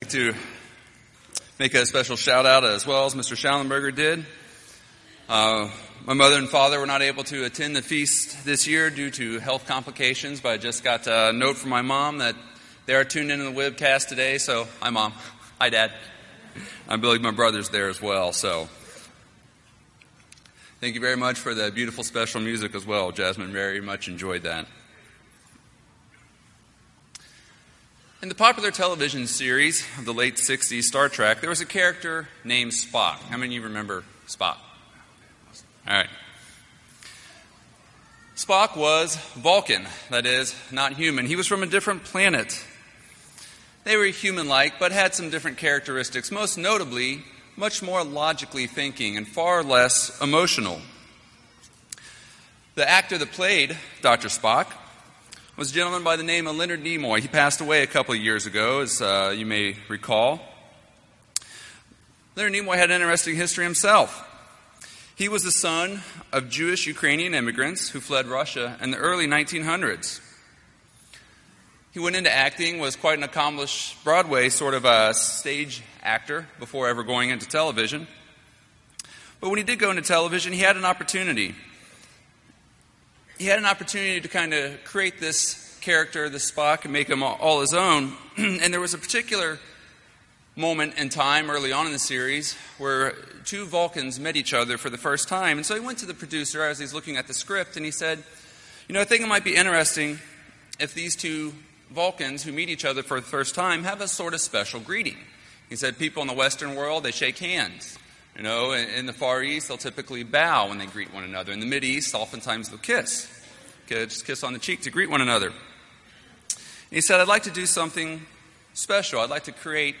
This sermon was given at the Panama City Beach, Florida 2018 Feast site.